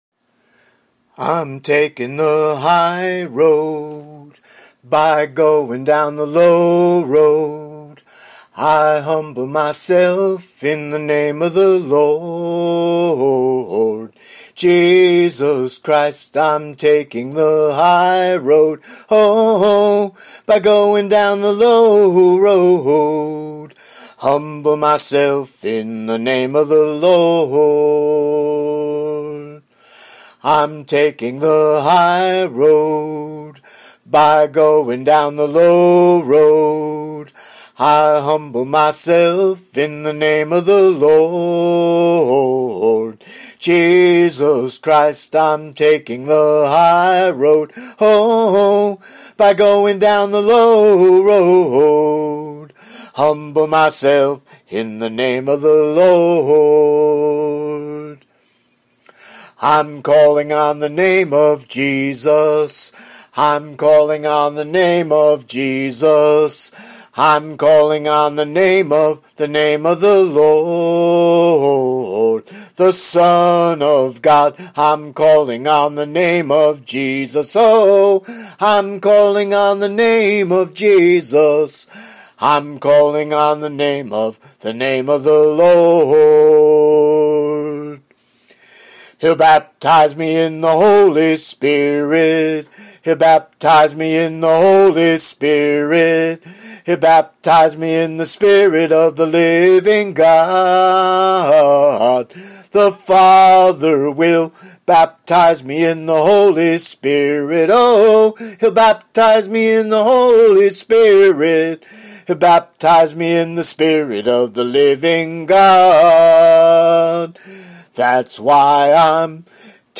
most are “a cappella” (voice only)